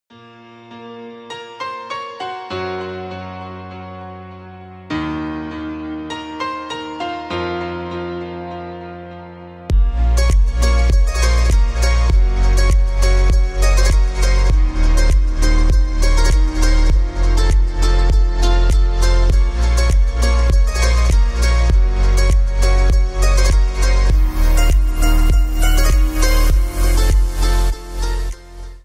инструментал